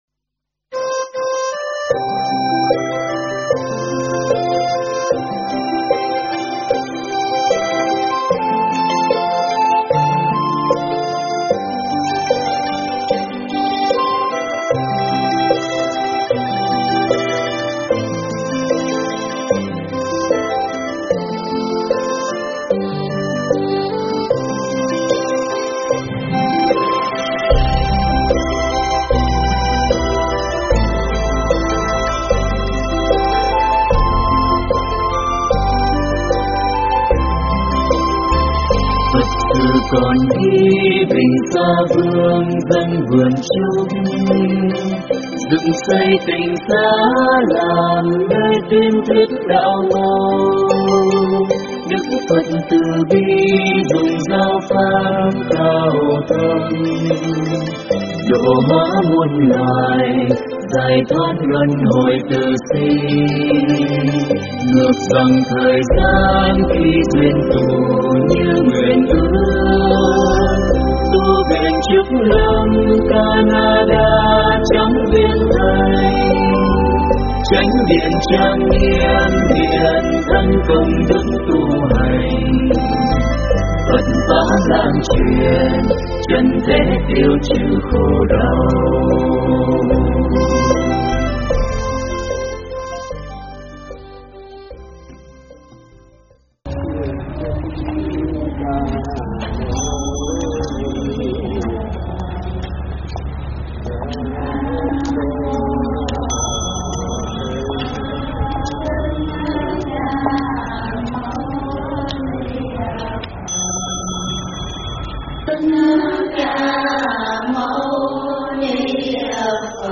Thuyết pháp
giảng tại Chùa Văn Thù Riverside